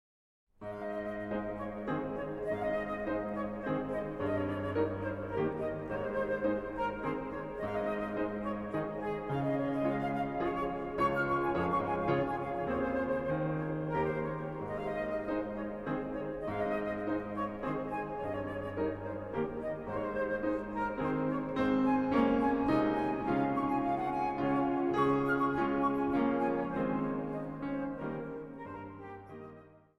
bariton
hobo
vleugel
dwarsfluit | begeleidingsorkest
orgel.
Zang | Mannenkoor